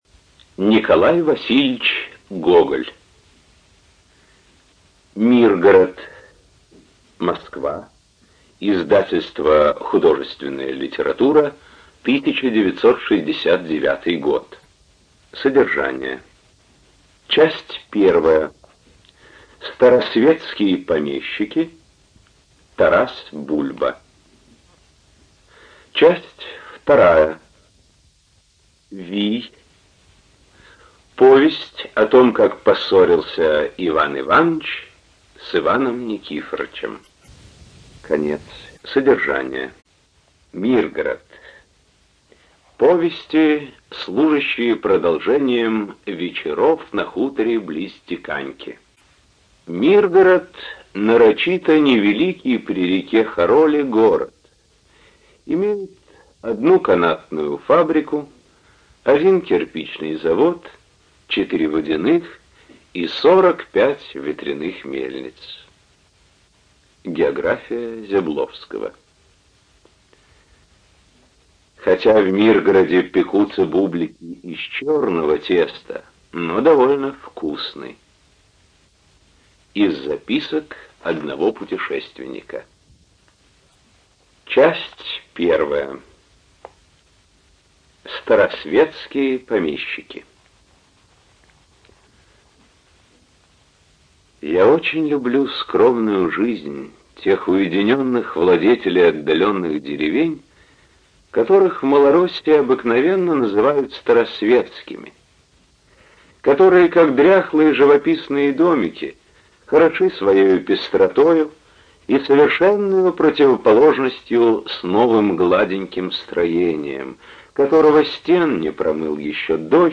ЖанрКлассическая проза
Студия звукозаписиЛогосвос